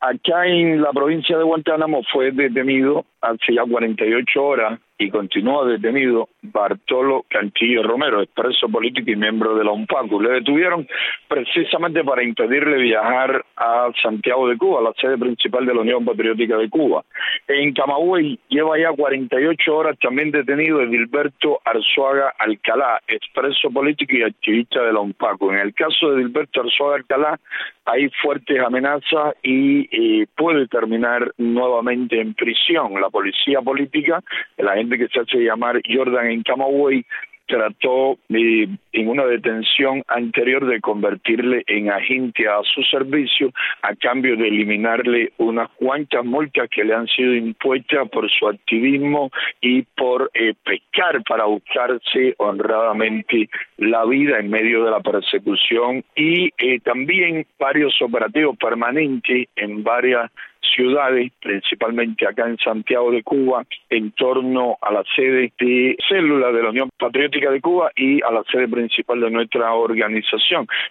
José Daniel Ferrer, líder de la organización, ofreció los detalles a Radio Martí.
Declaraciones de José Daniel Ferrer a Radio Martí